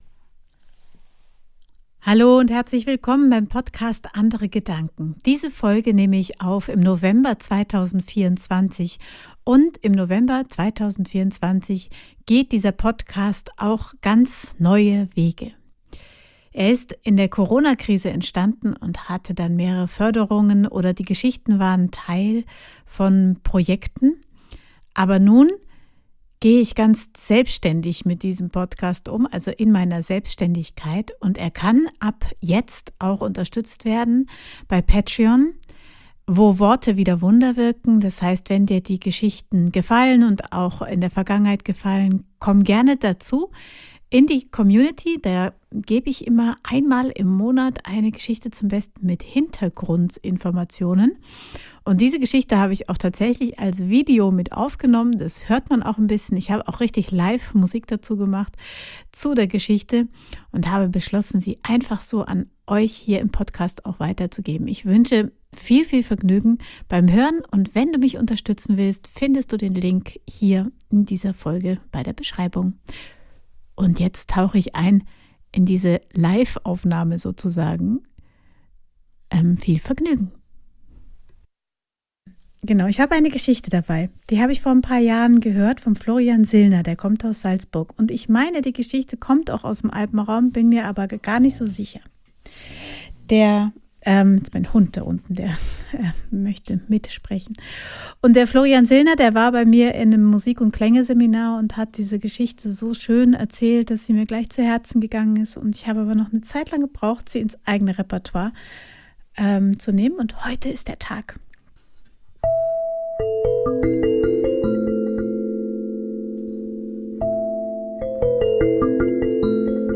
Ein Format der Storybox München frei erzählte Geschichten, Musik, Inspiration für Erwachsene